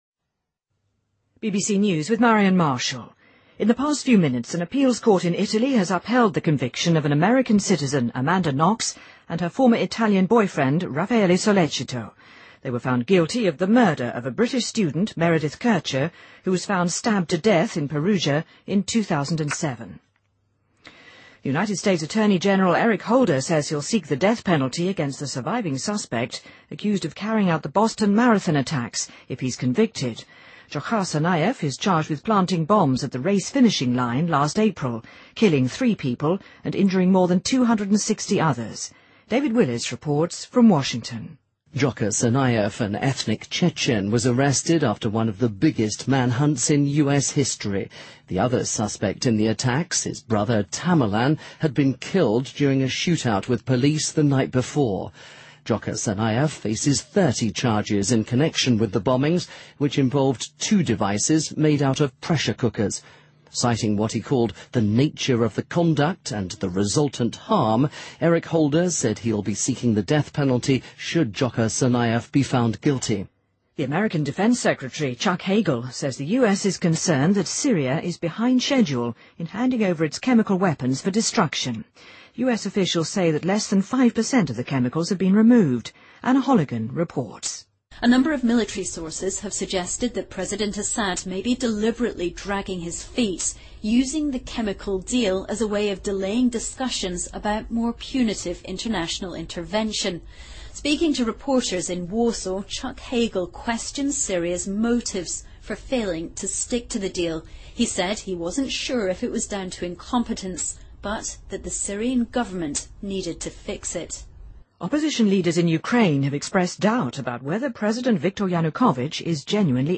BBC news,2014-01-31